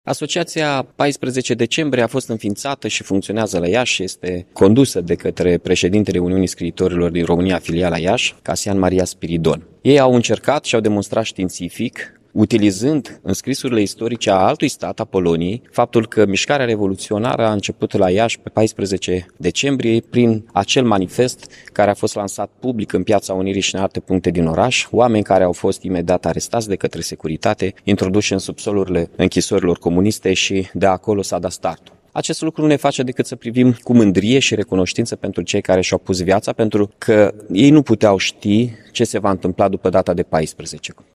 Astăzi, la Iași, în Piața Unirii din localitate, s-a desfășurat un ceremonial de depuneri de coroane de flori în memoria Momentului Zero al Revoluției Române.
În cuvântul său, edilul Mihai Chirica a subliniat faptul că după mulți ani, folosind arhivele din alte state, s-a reușit să se demonstreze că Revoluția a început la Iași.